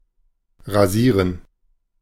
Ääntäminen
Ääntäminen US Tuntematon aksentti: IPA : /ʃeɪv/ Haettu sana löytyi näillä lähdekielillä: englanti Käännös Konteksti Ääninäyte Verbit 1. rasieren 2. sich rasieren 3. barbieren vanhahtava Substantiivit 4.